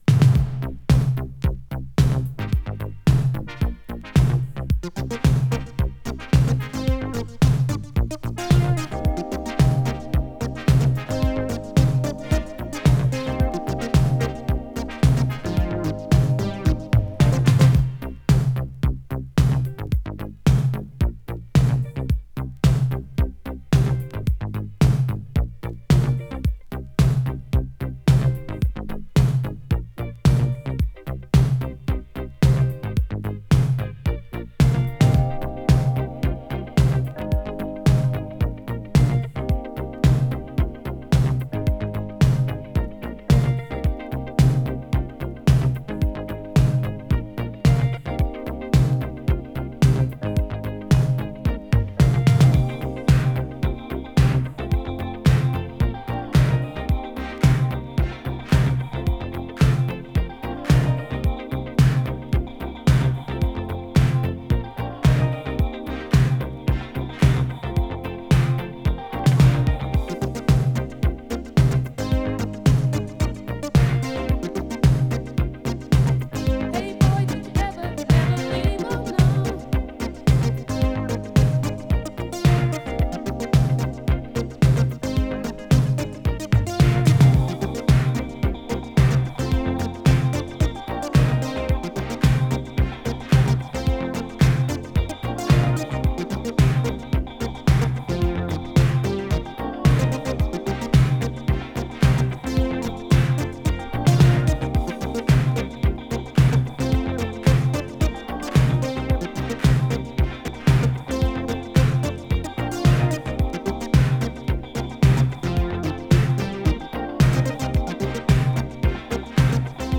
【BOOGIE】【DISCO】
(Instrumental)